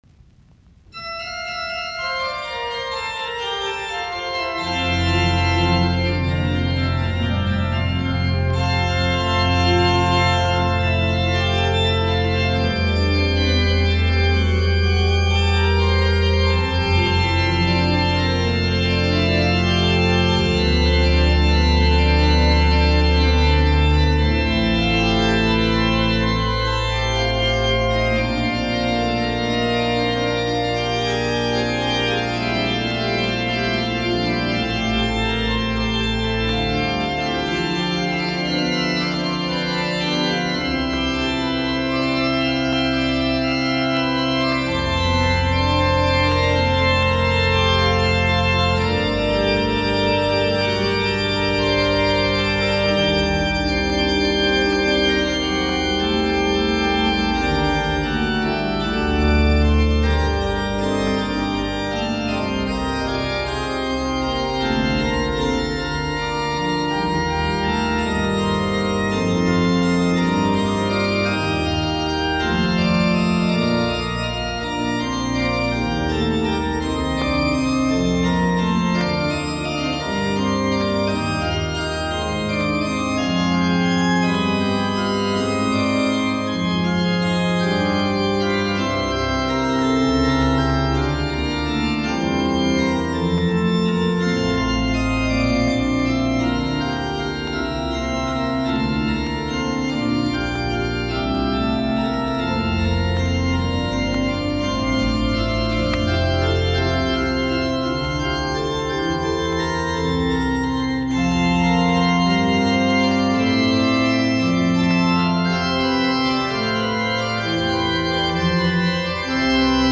Soitinnus: Urut.
Soro, Monastery Church.